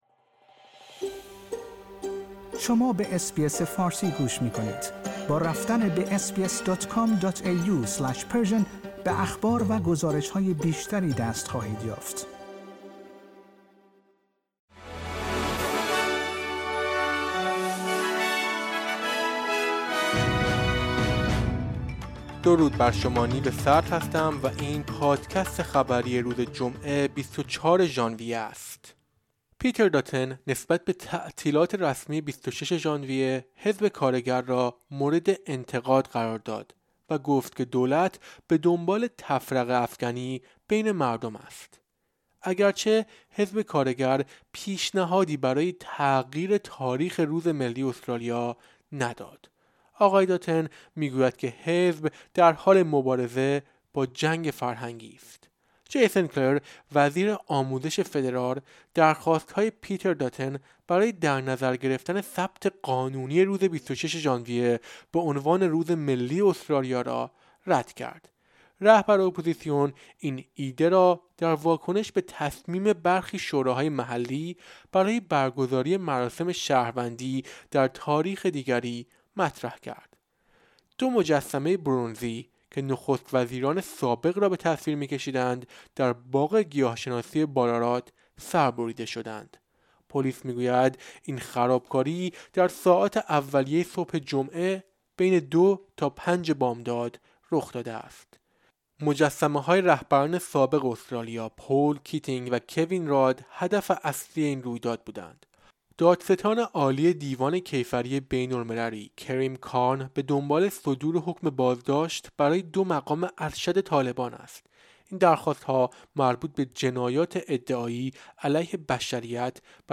در این پادکست خبری مهمترین اخبار استرالیا در روز جمعه ۲۴ ژانویه ۲۰۲۵ ارائه شده است.